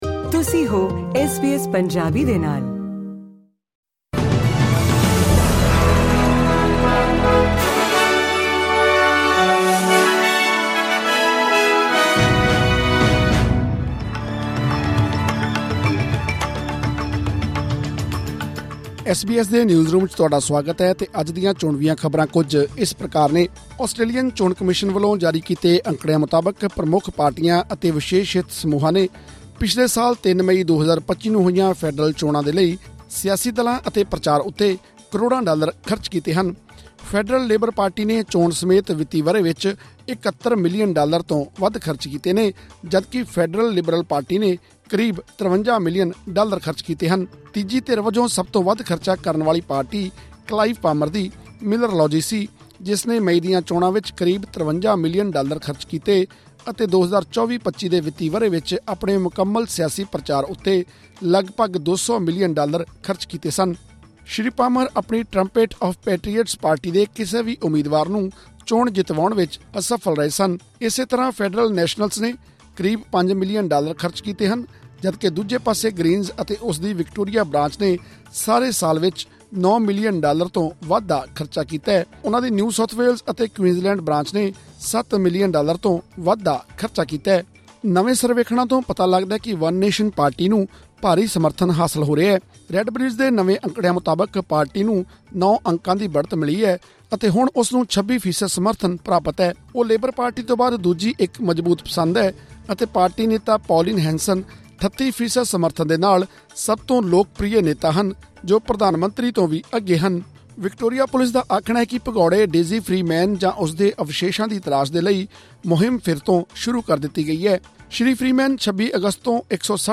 ਆਸਟ੍ਰੇਲੀਅਨ ਚੋਣ ਕਮਿਸ਼ਨ ਦੇ ਅੰਕੜਿਆਂ ਅਨੁਸਾਰ 3 ਮਈ 2025 ਦੀਆਂ ਫੈਡਰਲ ਚੋਣਾਂ ਲਈ ਪ੍ਰਮੁੱਖ ਸਿਆਸੀ ਪਾਰਟੀਆਂ ਨੇ ਕਰੋੜਾਂ ਡਾਲਰ ਖਰਚੇ। ਲੇਬਰ ਪਾਰਟੀ ਨੇ ਚੋਣ ਸਮੇਤ 71 ਮਿਲੀਅਨ ਡਾਲਰ ਤੋਂ ਵੱਧ, ਲਿਬਰਲ ਪਾਰਟੀ ਨੇ ਕਰੀਬ 53 ਮਿਲੀਅਨ, ਨੈਸ਼ਨਲਜ਼ ਨੇ ਲਗਭਗ 5 ਮਿਲੀਅਨ ਅਤੇ ਗ੍ਰੀਨਜ਼ ਨੇ 9 ਮਿਲੀਅਨ ਡਾਲਰ ਤੋਂ ਵੱਧ ਖਰਚ ਕੀਤਾ। ਹੋਰ ਵੇਰਵੇ ਲਈ ਸੁਣੋ ਇਹ ਖ਼ਬਰਨਾਮਾ।